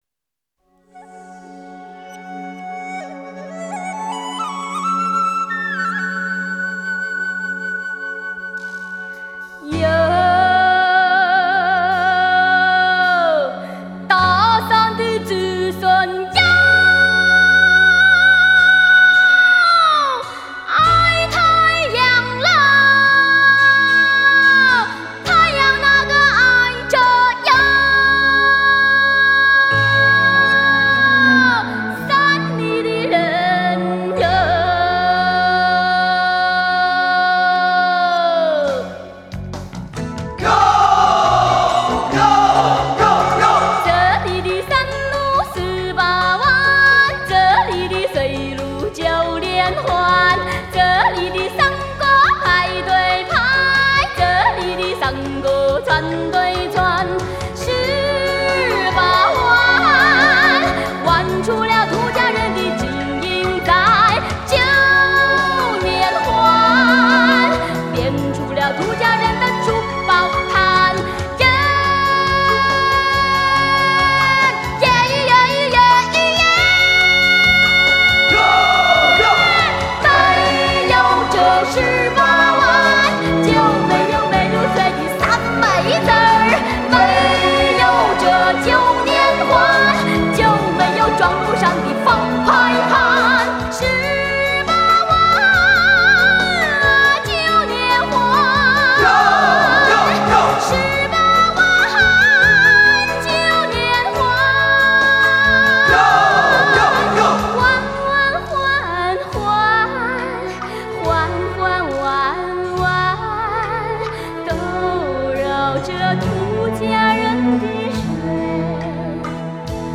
Ps：在线试听为压缩音质节选，体验无损音质请下载完整版 哟……大山的子孙 哟 爱太阳喽 太阳那个爱着哟 山里的人哟……